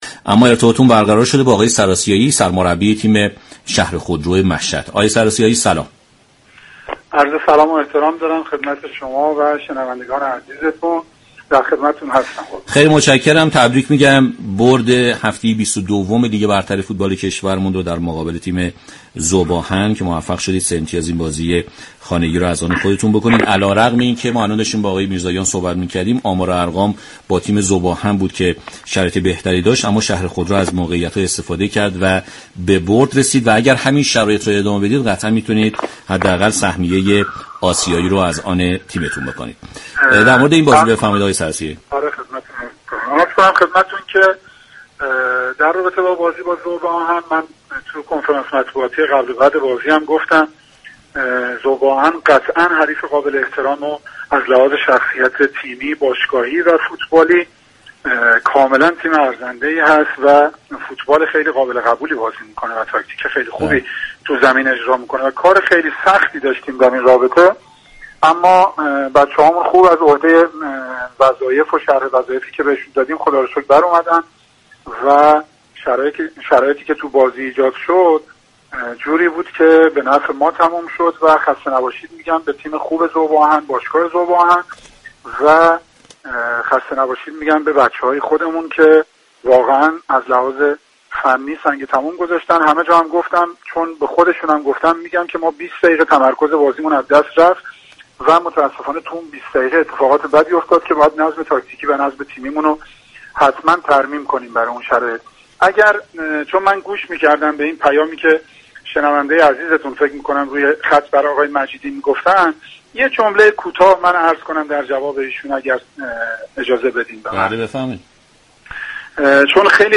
به طور زنده از شبكه رادیویی ورزش روی آنتن رفت.